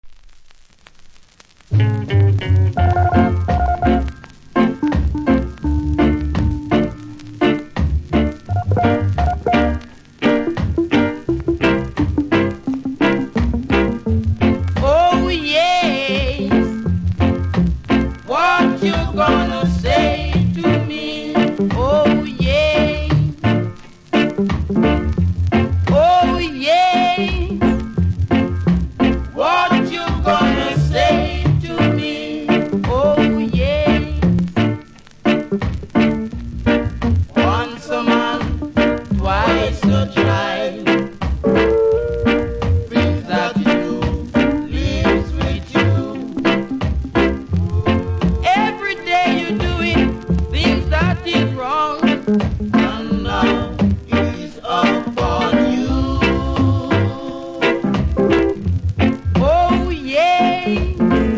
キズ多めでノイズもそこそこありますので試聴で確認下さい。